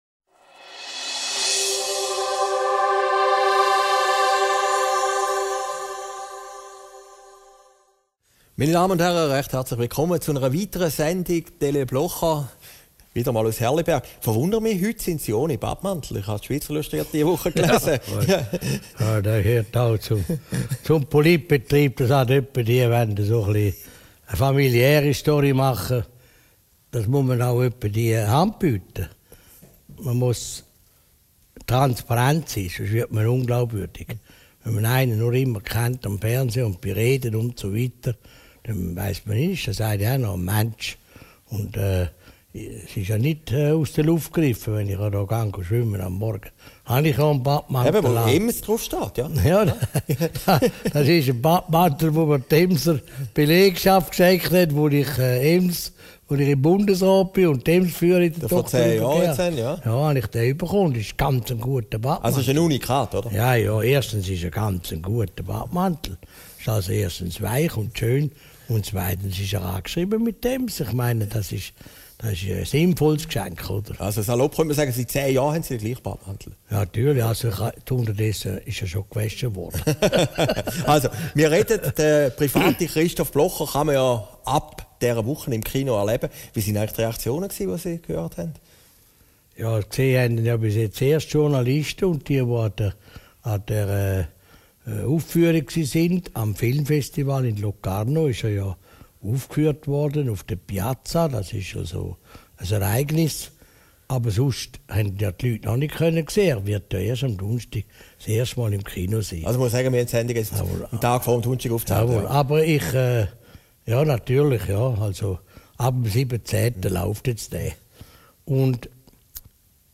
Aufgezeichnet in Herrliberg, 15. Oktober 2013